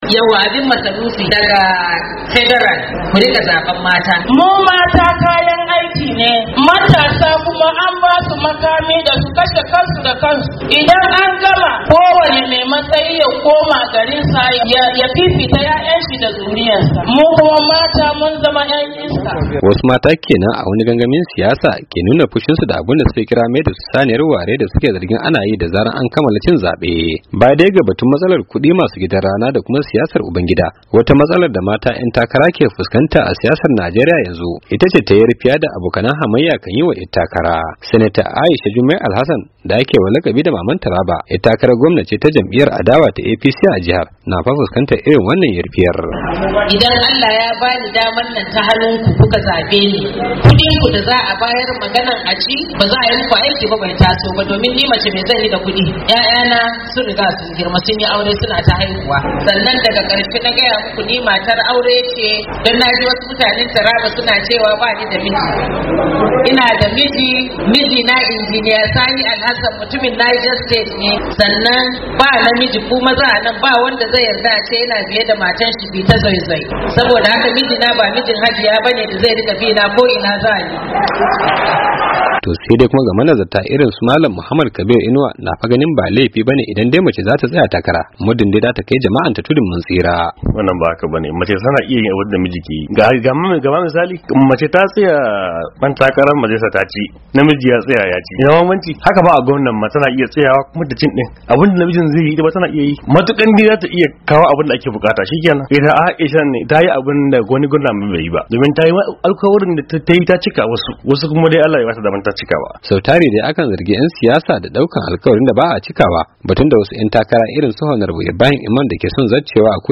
Saurari rahotan